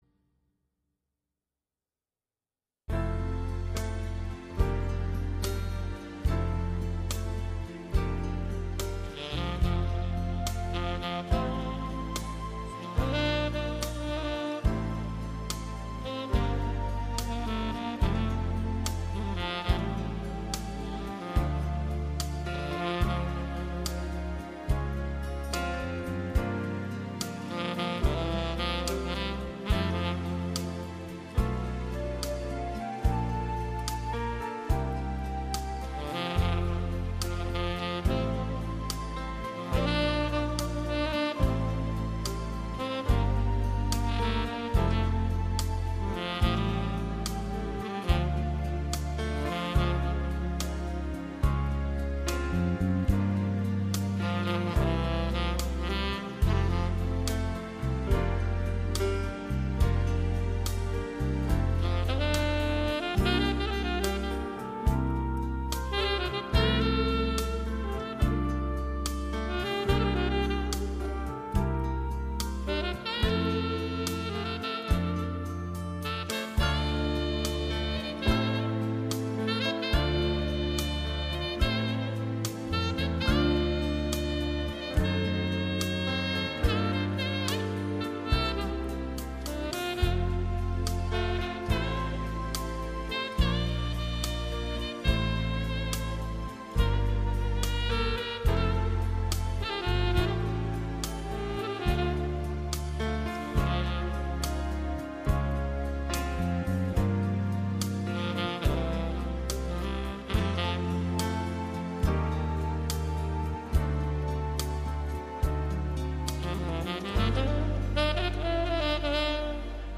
Wedding Samples